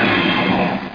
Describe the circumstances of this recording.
1 channel